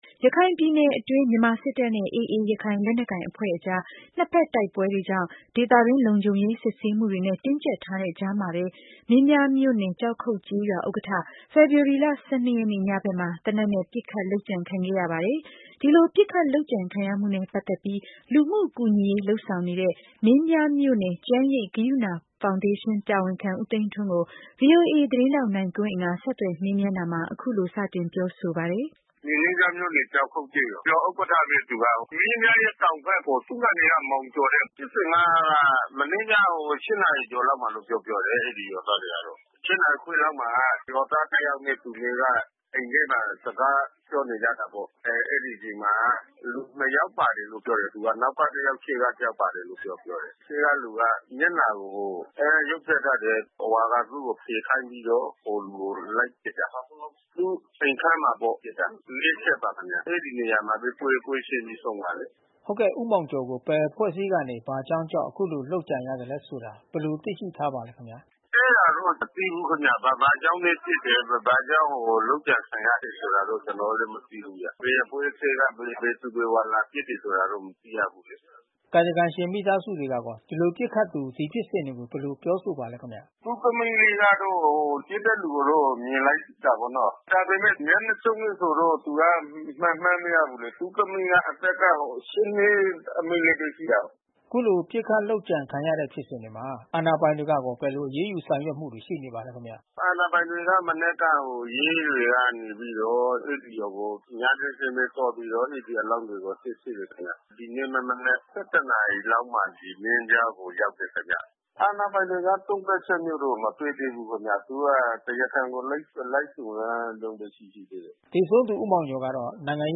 ဆက်သွယ် မေးမြန်းရာမှာ အခုလိုစတင်ပြောဆိုပါတယ်။